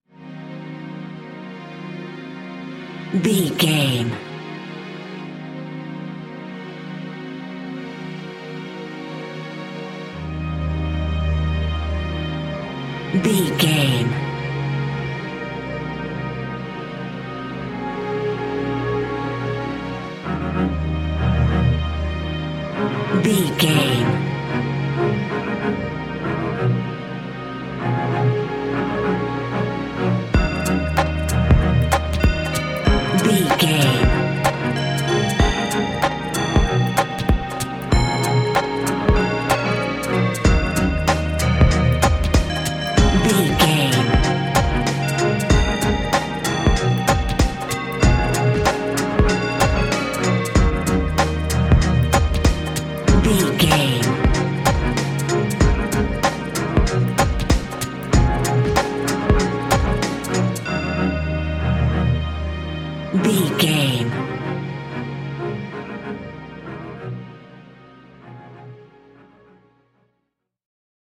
Aeolian/Minor
E♭
electronic
techno
trance
industrial
drone
glitch
synth lead
synth bass